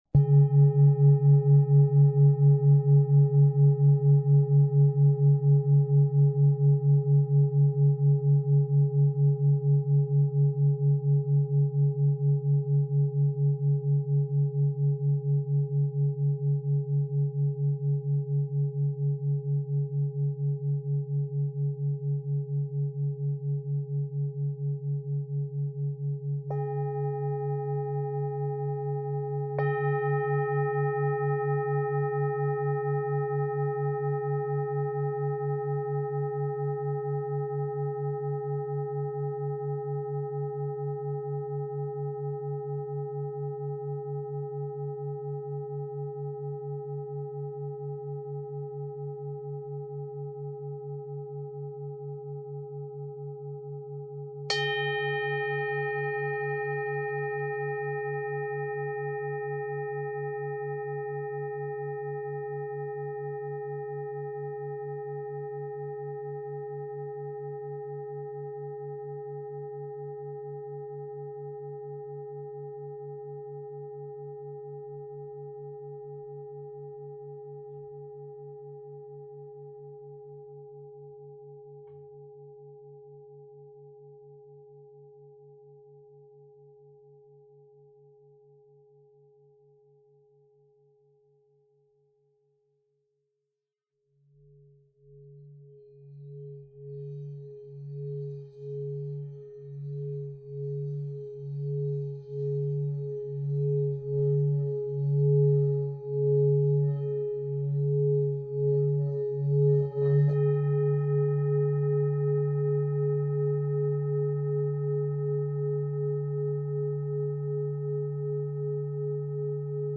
Tibetská misa ø 24 cm/tón G#
Hraním na Tibetskú misu sa jej stena rozvibruje a vydáva nádherné harmonizujúci tóny.
Táto misa je dovezené z Indie a jej základný tón je G#
Materiál kov/mosadz